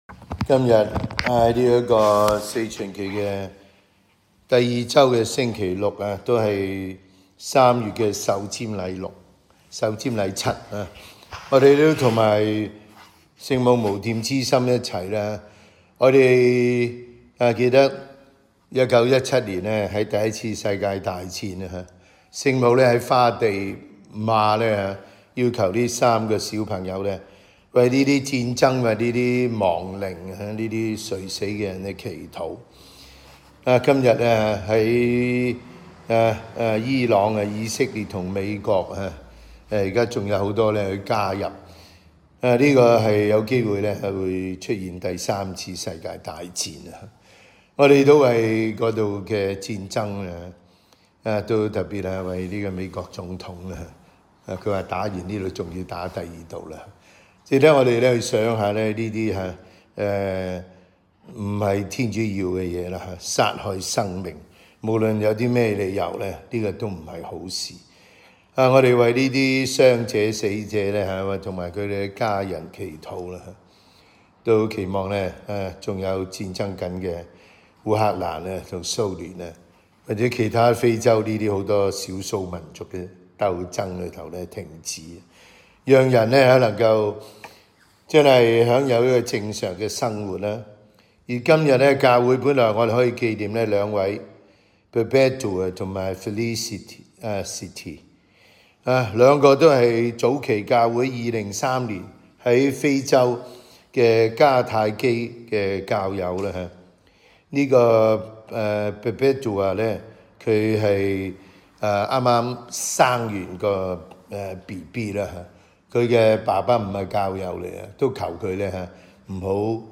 SDB 每日講道及靈修講座